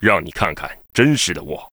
文件 文件历史 文件用途 全域文件用途 Bk_fw_05.ogg （Ogg Vorbis声音文件，长度1.7秒，119 kbps，文件大小：25 KB） 源地址:游戏语音 文件历史 点击某个日期/时间查看对应时刻的文件。